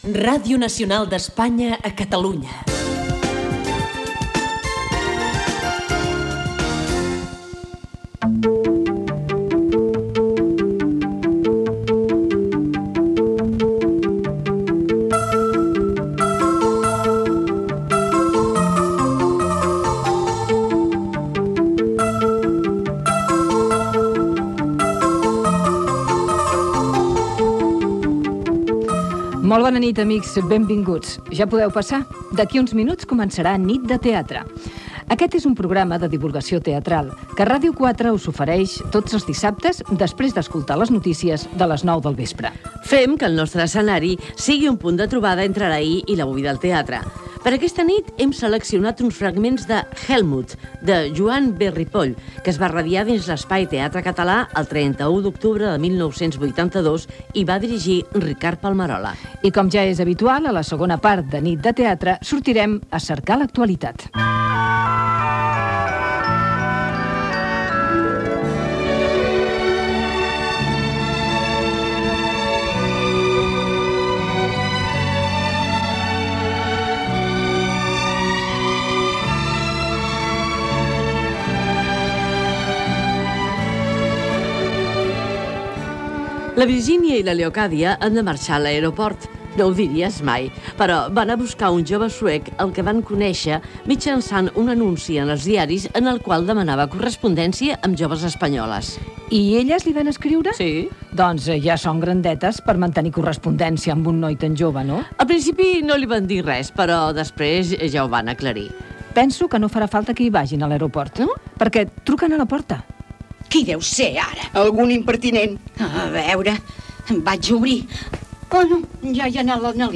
Indicatiu de l'emissora, careta del programa, presentació del programa i de l'adaptació radiofònica de l'obra "Helmut"